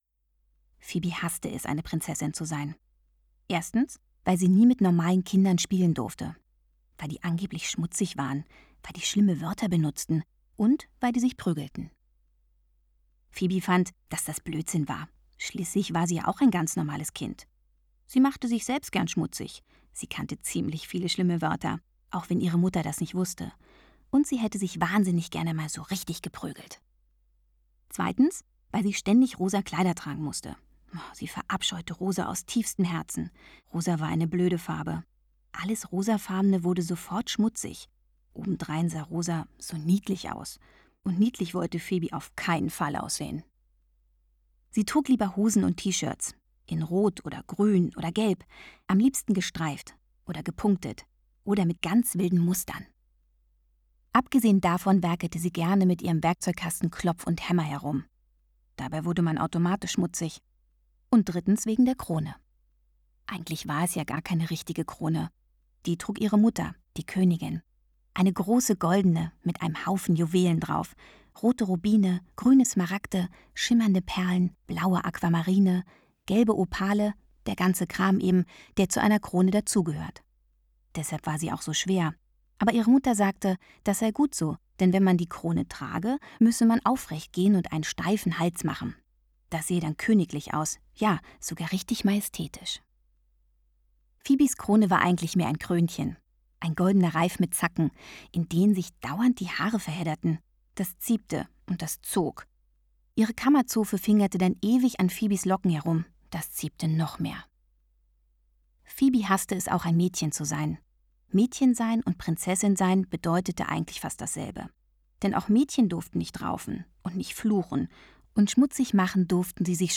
Alexandra Neldel (Sprecher)
Die Abenteuer von Prinzessin Fibi liest sie mit Witz und Augenzwinkern. Dabei wird sie von zarter Gitarrenmusik begleitet.